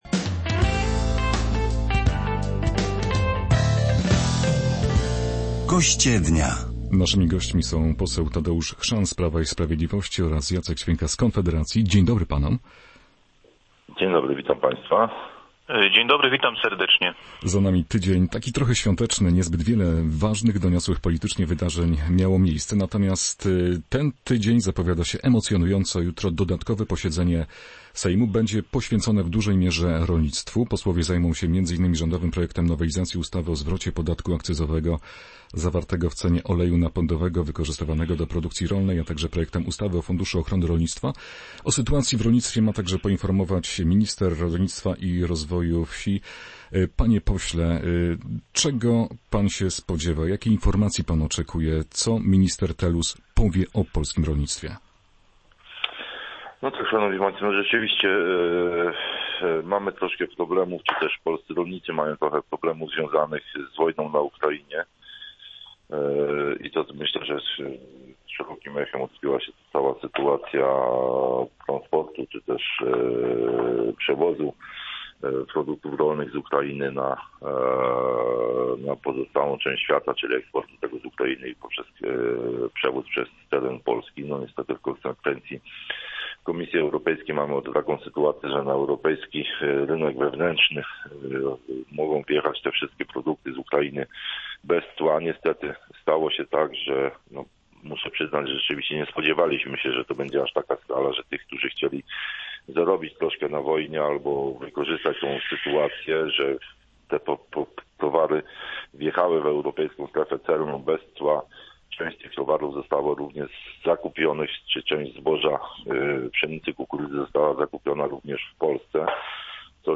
Więcej na ten temat w rozmowie